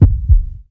Play Pulse Sound Effect - SoundBoardGuy
heartbeatloop.mp3